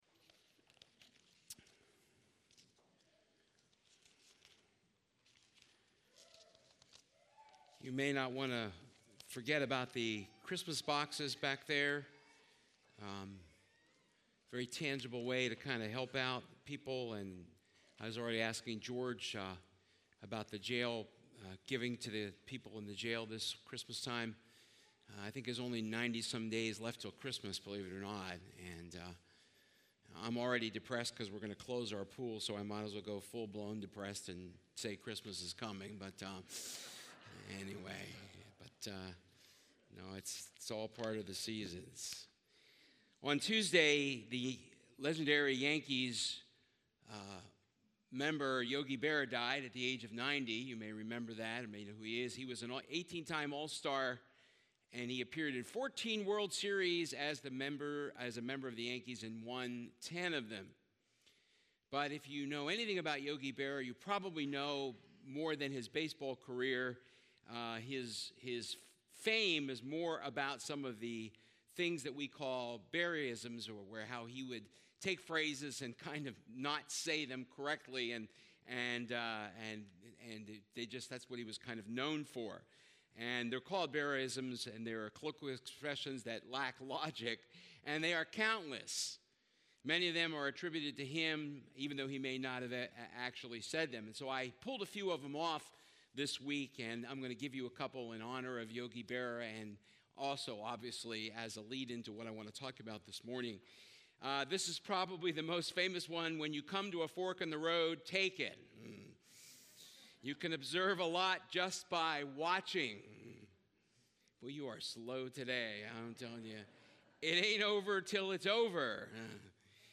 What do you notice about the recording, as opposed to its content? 1 Service Type: Sunday Service The mindset or attitude that we have « September 13